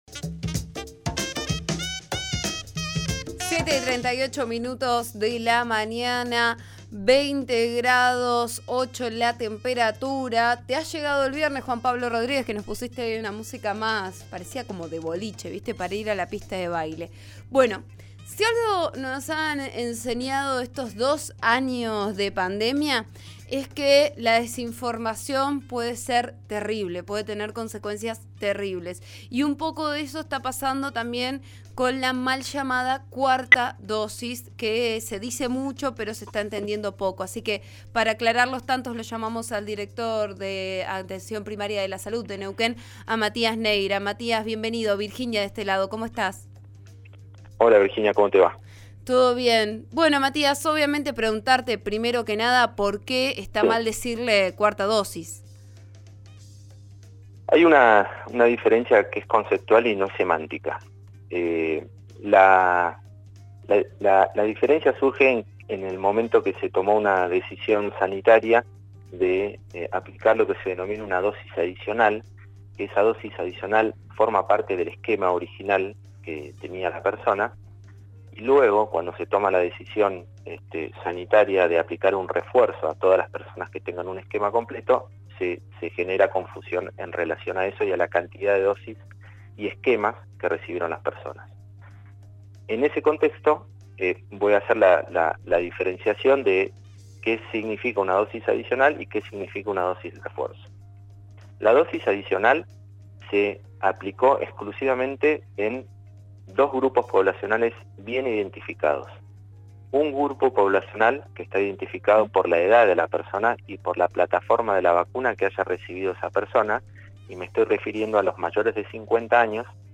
El director Provincial de Atención Primaria de la Salud, Matías Neira, informó a «Vos a Diario» (RN DIARIO 89.3) que analizarán cada escuela en relación a la matricula y la cobertura de vacunación para determinar en cuáles se vacunará, de ser necesario.